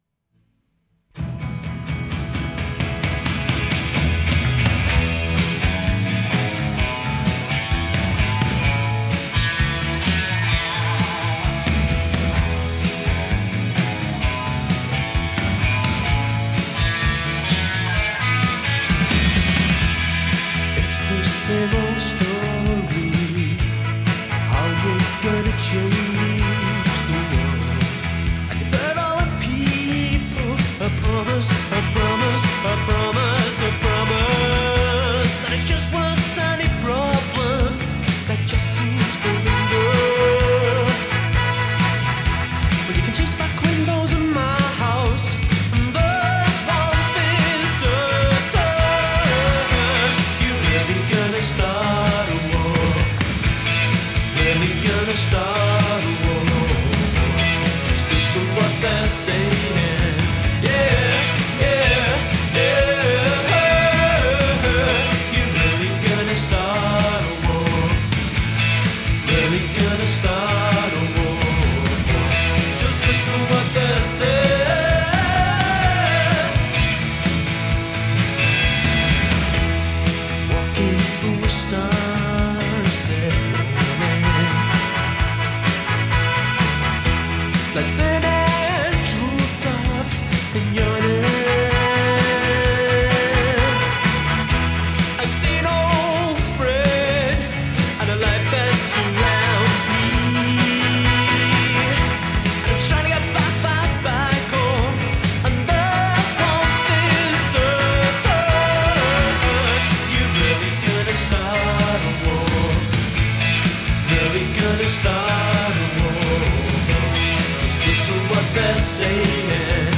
Genre: Indie, Lo-Fi, New Wave